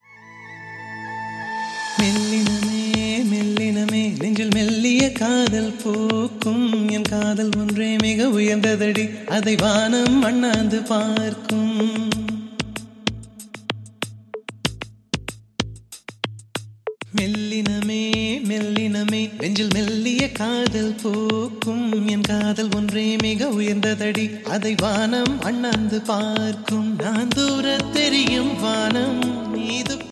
best flute ringtone download
romantic ringtone